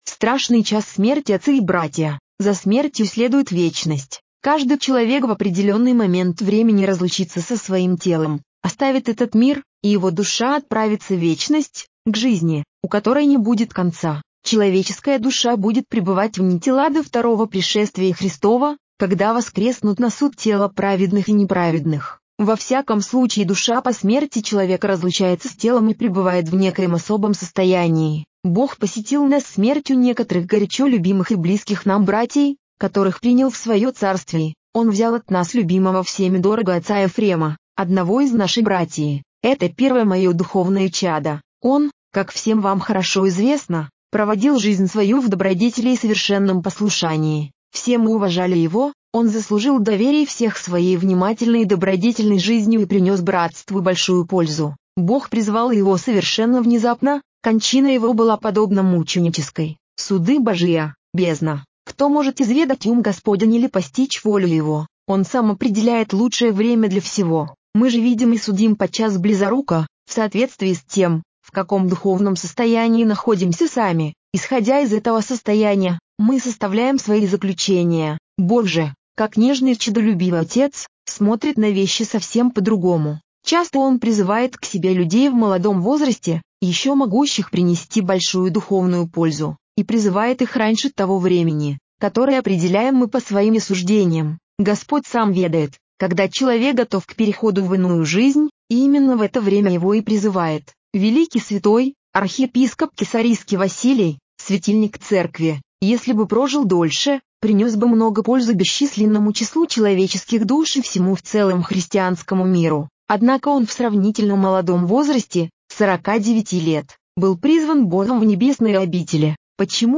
Проповедь арх. Ефрема Аризонского. Произнесено в монастыре Филофей 30 ноября 1984 года.